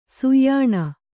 ♪ suyyāṇa